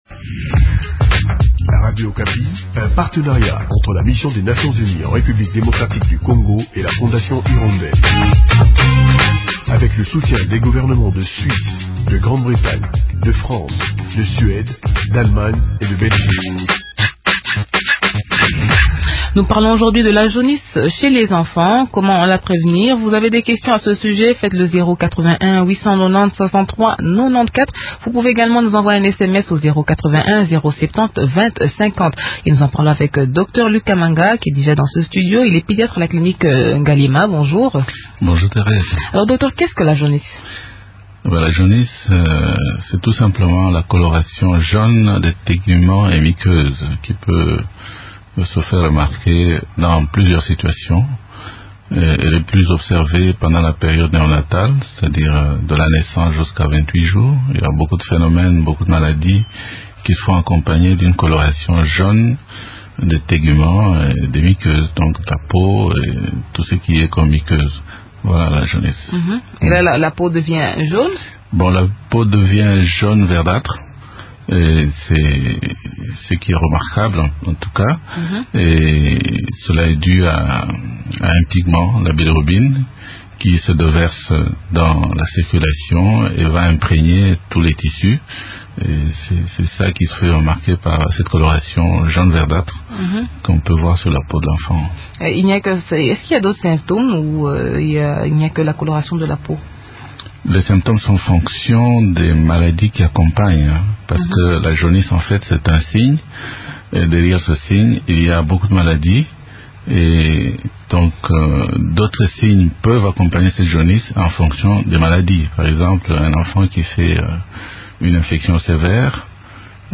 Des éléments de réponse dans cette interview